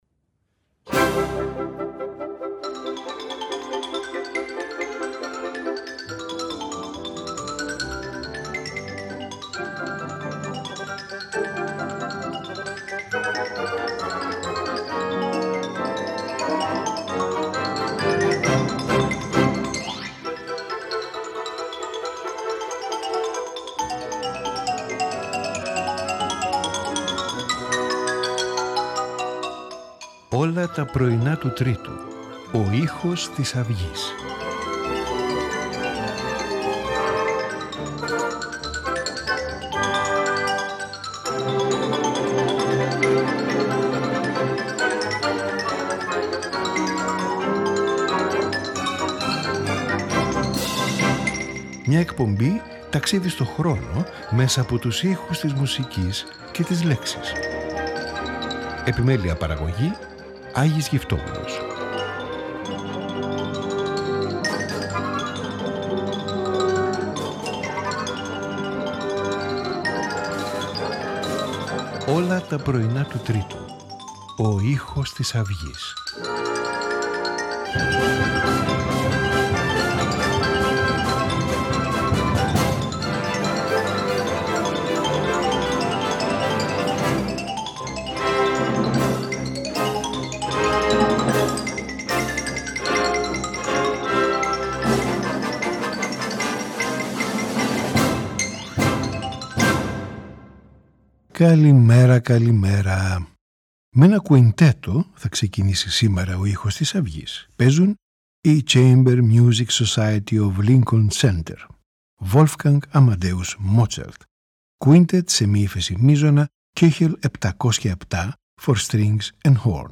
For Strings and Horn
for piano 4 hands